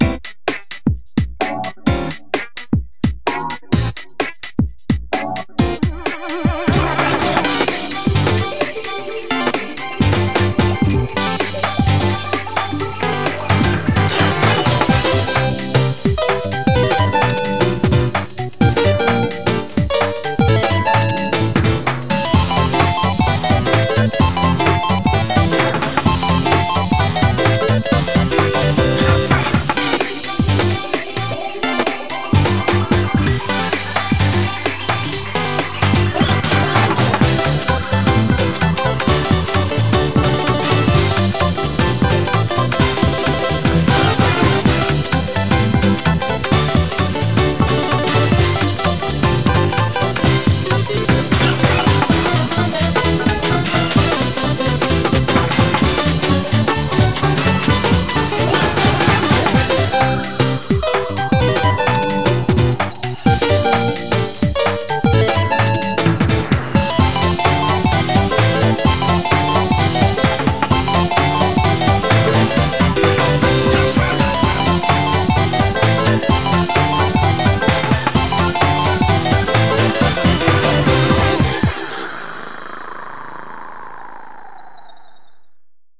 BPM129
Audio QualityPerfect (High Quality)
Better quality audio.